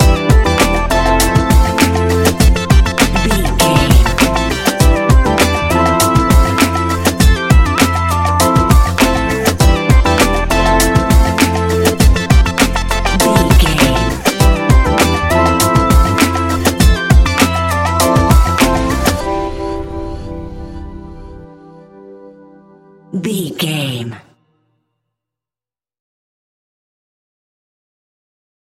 Ionian/Major
A♭
chilled
laid back
Lounge
sparse
chilled electronica
ambient